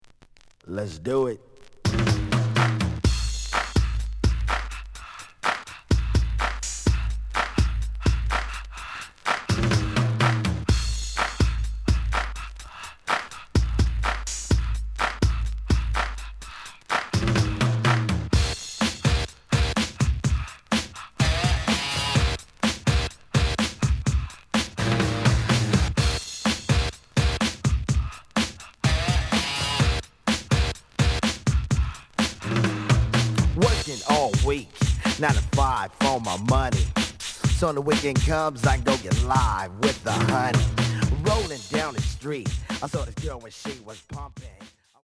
試聴は実際のレコードから録音しています。
●Genre: Hip Hop / R&B
●Record Grading: VG~VG+ (両面のラベルに若干のダメージ。)